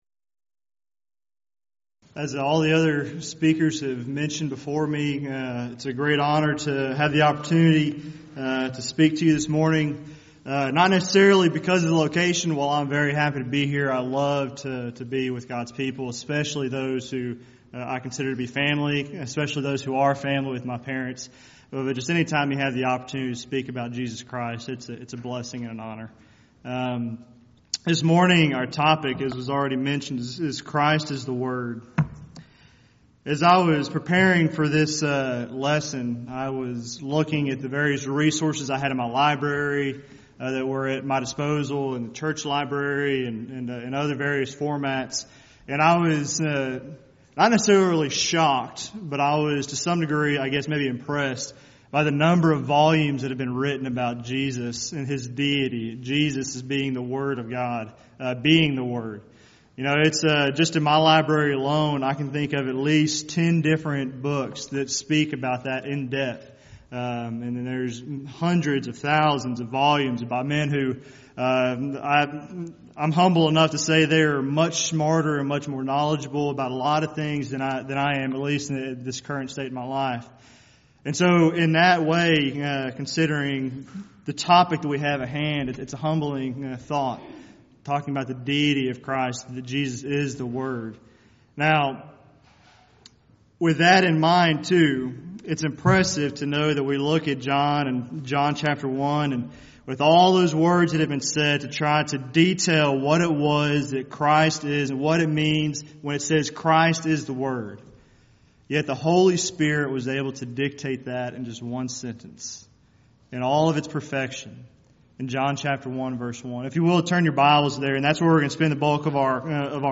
Alternate File Link File Details: Series: Back to the Bible Lectures Event: 8th Annual Back To The Bible Lectures Theme/Title: Do You Know The Christ?
lecture